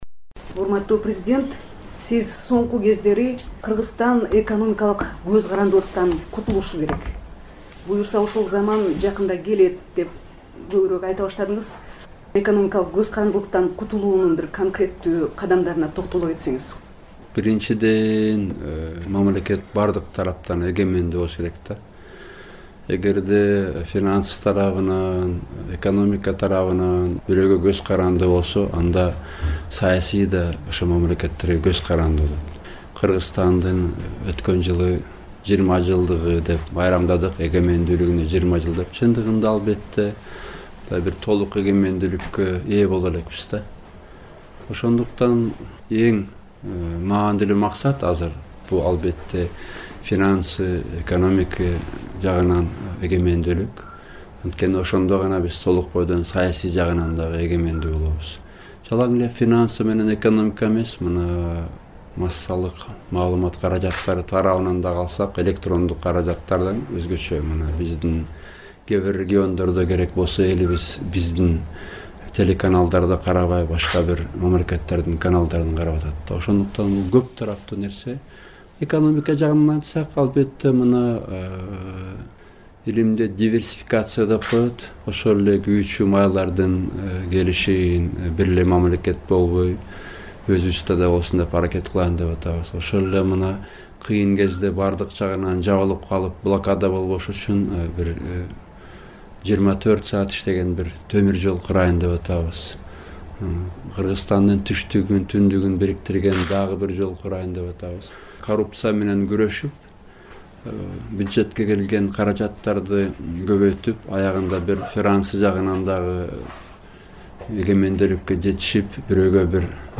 Президент Атамбаевдин маеги (1-бөлүк)